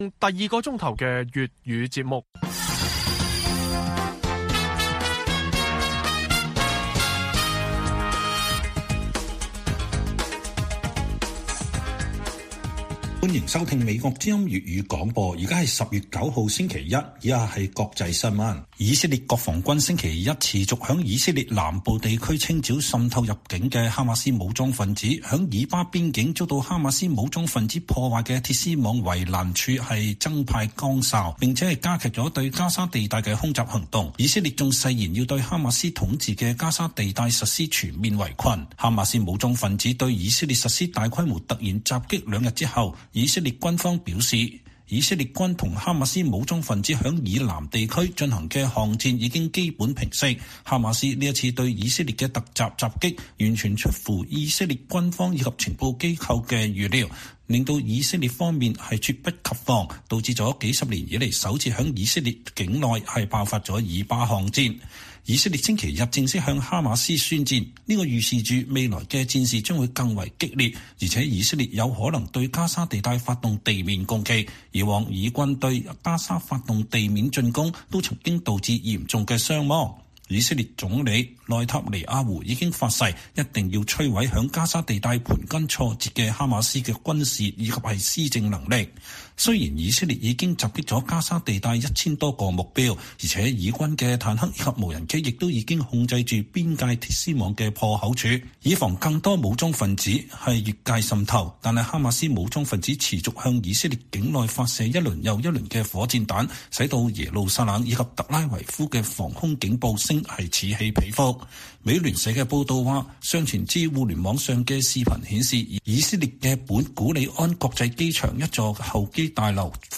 粵語新聞 晚上10-11點: 以色列誓言全面圍困加沙，強化反擊哈馬斯，清剿境內武裝分子